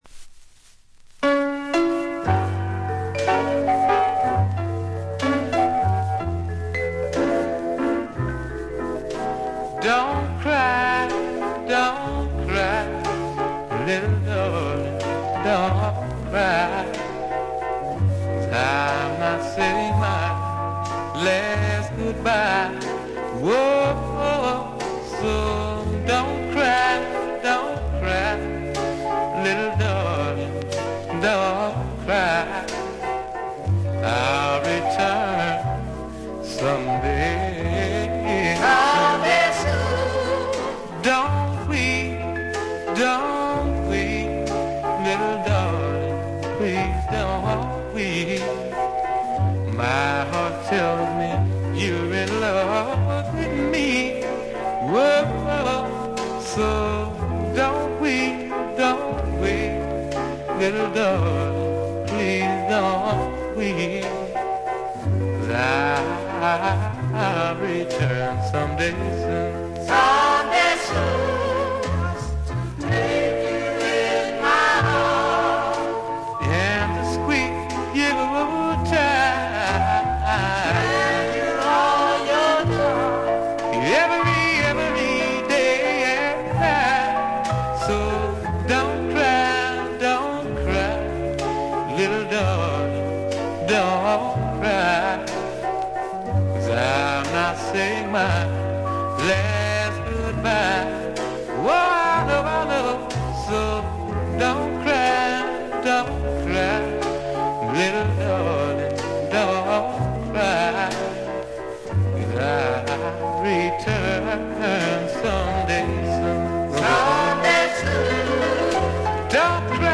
「くもり」のためか全体に軽微なバックグラウンドノイズが出ますが鮮度は良好です。ところどころでチリプチ。
試聴曲は現品からの取り込み音源です。